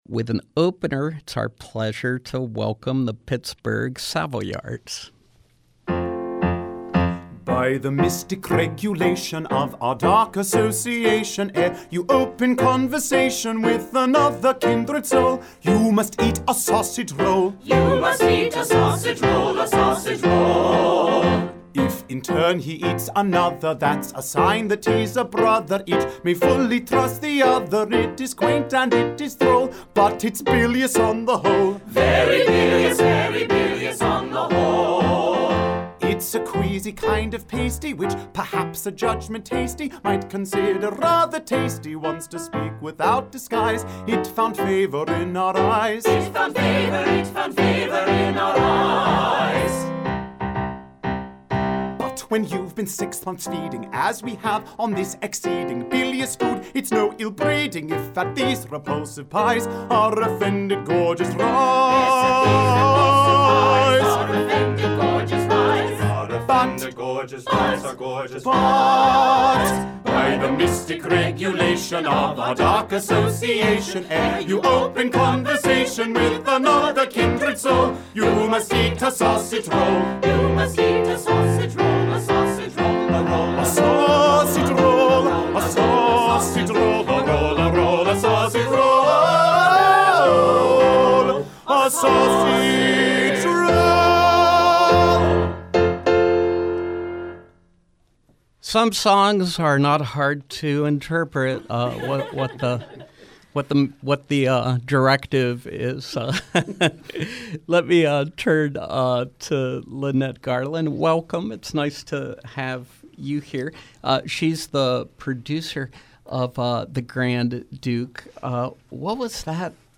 Live Music: The Grand Duke, Pittsburgh Savoyards